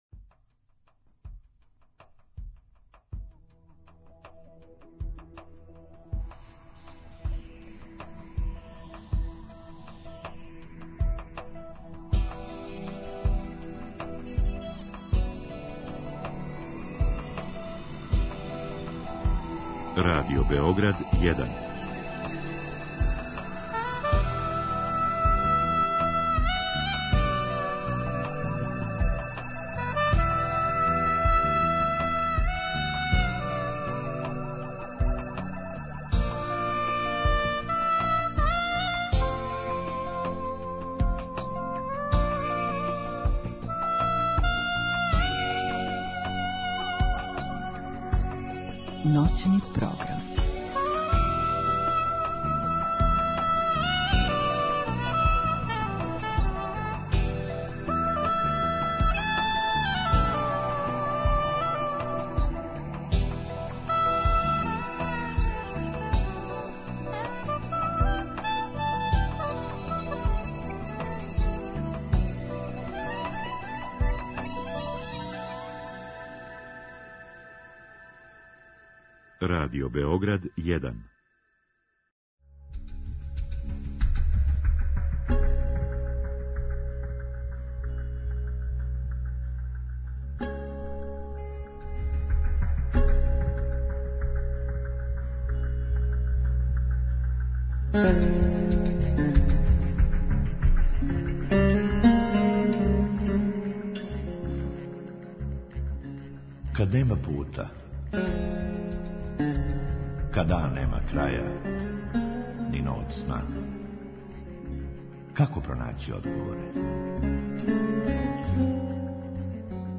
У другом сату слушаоци могу поставити питање нашој гошћи у директном програму.